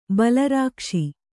♪ bala rākṣi